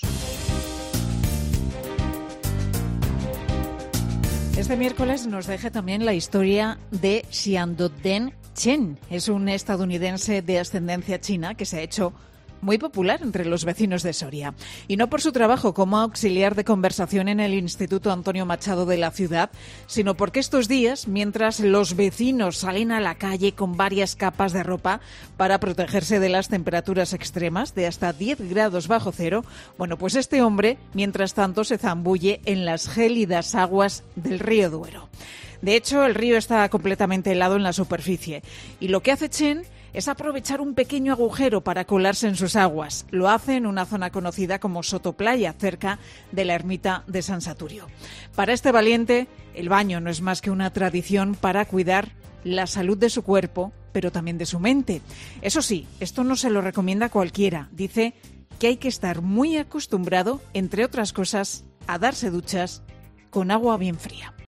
Presentado por Carlos Herrera, el comunicador más escuchado de la radio española, es un programa matinal que se emite en COPE, de lunes a viernes, de 6 a 13 horas, y que siguen cada día más de dos millones y medio de oyentes, según el EGM.